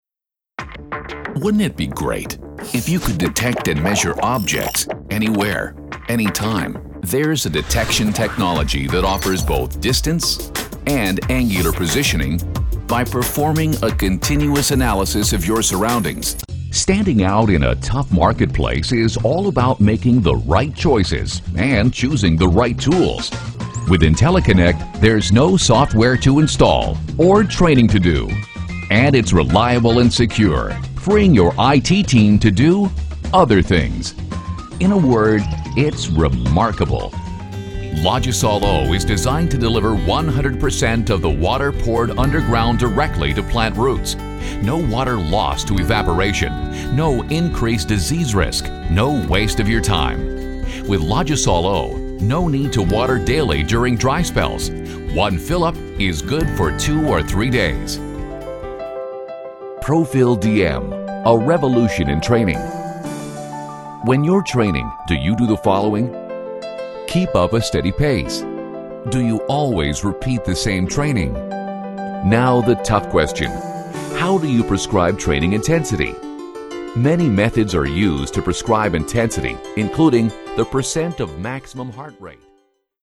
VOICE OVER DEMOS
Explainer Video Voice Over